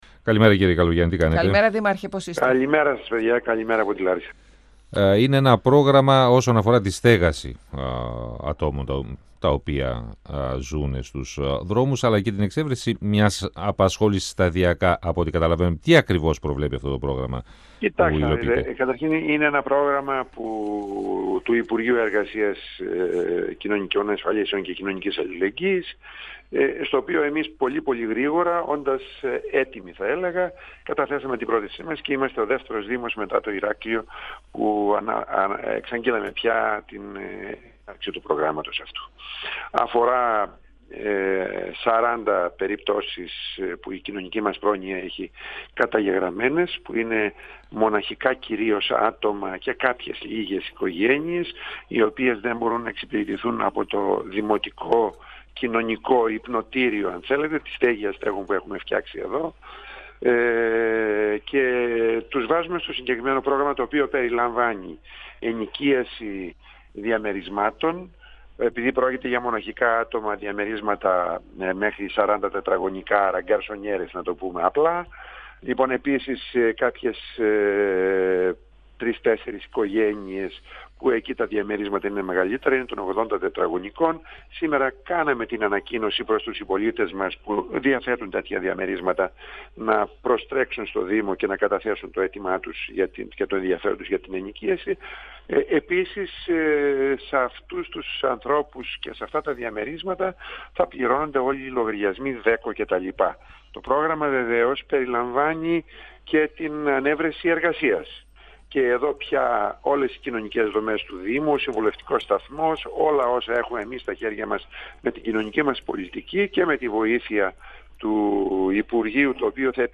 Ο  δήμαρχος Λαρισαίων Απόστολος Καλογιάννης στον 102FM του Ρ.Σ.Μ. της ΕΡΤ3
Στο πλευρό των αστέγων σπεύδει ο δήμος Λαρισαίων. Μετά το Ηράκλειο θα υλοποιηθεί στη Λάρισα το πρόγραμμα του υπουργείου Εργασίας που στοχεύει στη στέγαση περίπου 40 ατόμων τα οποία βρίσκονται στους δρόμους, ανέφερε ο  δήμαρχος Απόστολος Καλογιάννης, μιλώντας στον 102FM του Ραδιοφωνικού Σταθμού Μακεδονίας της ΕΡΤ3.